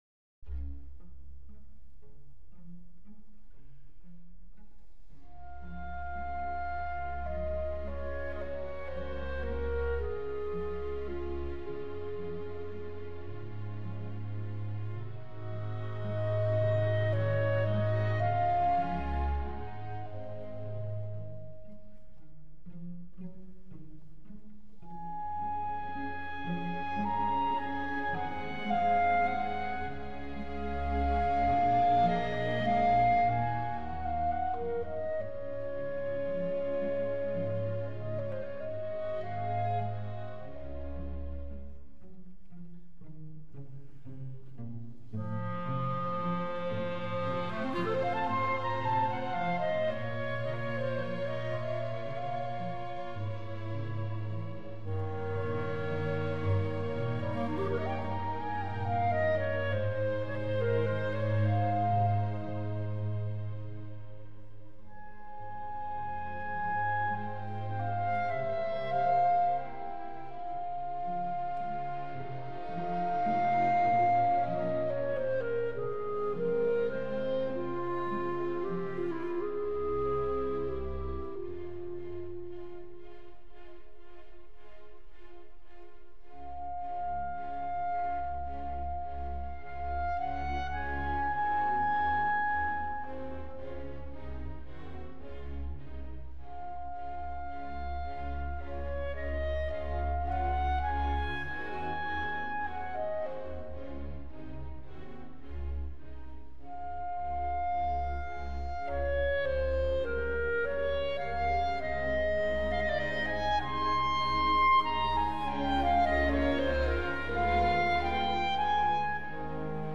所屬時期／樂派        浪漫主義